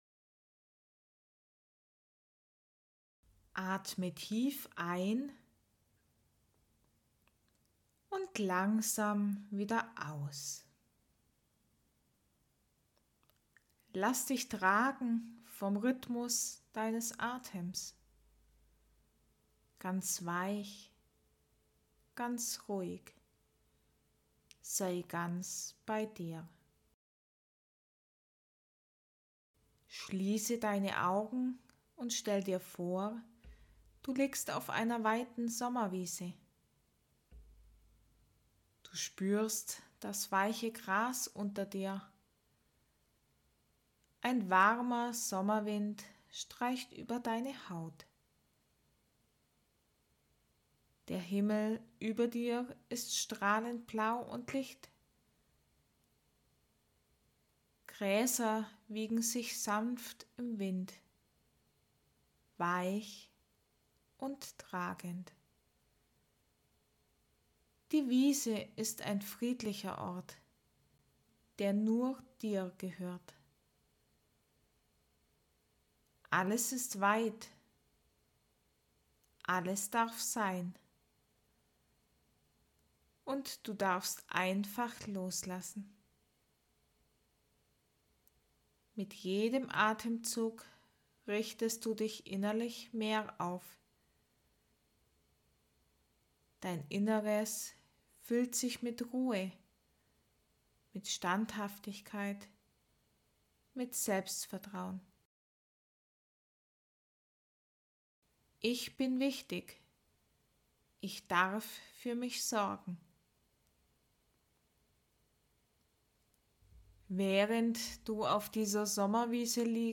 Diese kurze Hypnose unterstützt dich dabei, dich abzugrenzen,